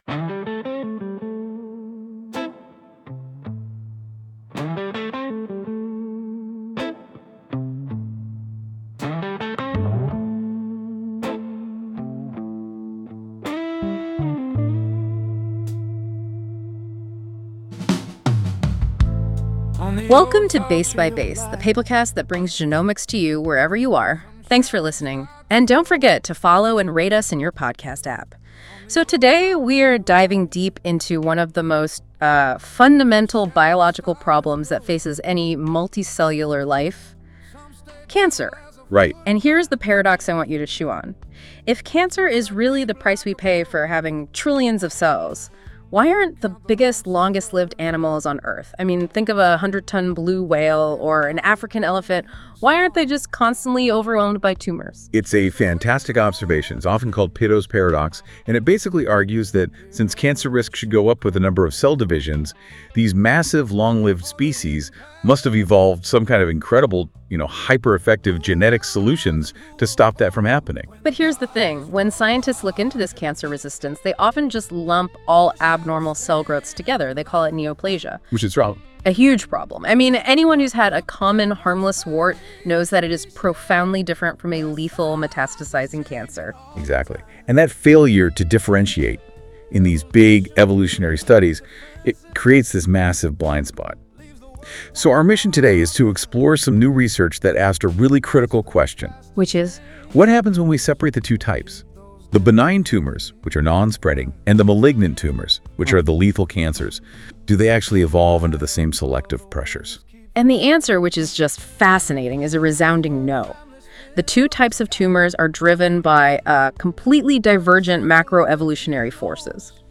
Divergent Evolutionary Dynamics of Benign and Malignant Tumors Music:Enjoy the music based on this article at the end of the episode.